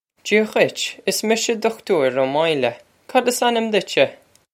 Jee-ah ghwitch. Iss misha Dukh-toor Oh Moyil-eh. Cod iss an-im ditch-sheh?
This is an approximate phonetic pronunciation of the phrase.